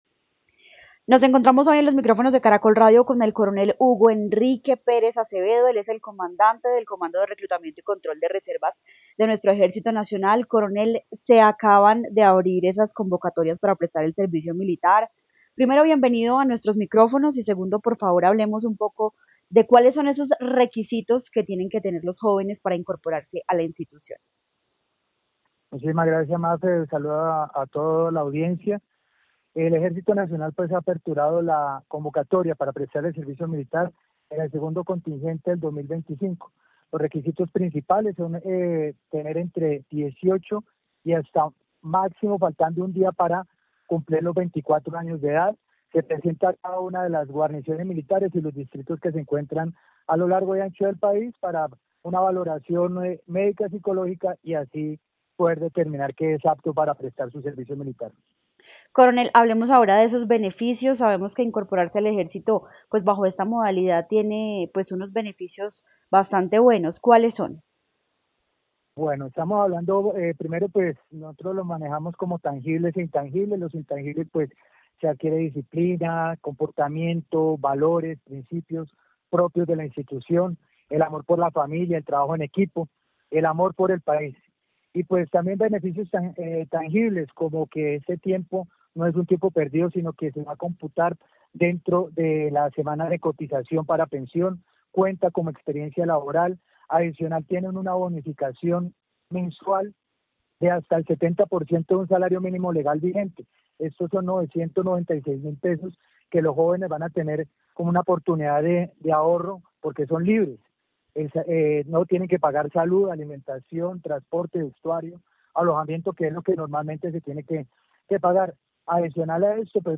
El Ejército Nacional abrió oficialmente la convocatoria para el segundo contingente del servicio militar del año 2025. Así lo anunció el coronel Hugo Enrique Pérez Acevedo, comandante del Comando de Reclutamiento y Control de Reservas, en entrevista con Caracol Radio.